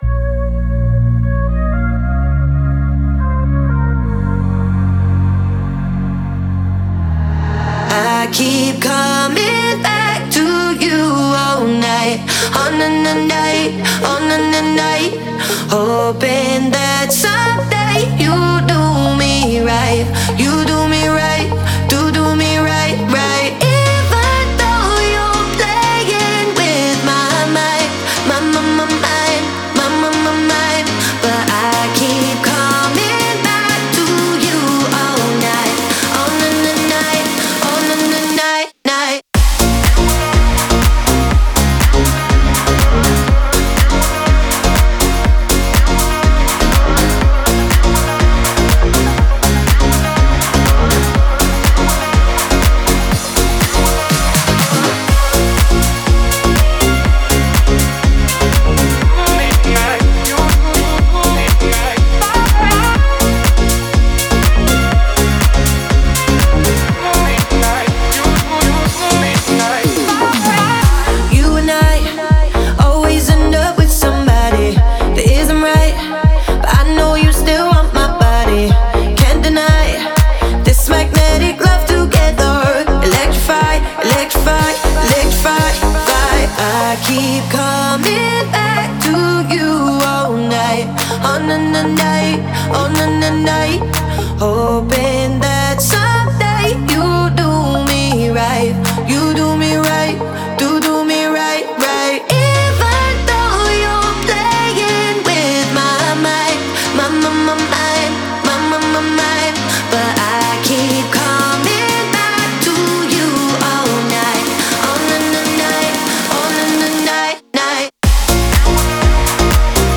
это энергичная танцевальная композиция в жанре хаус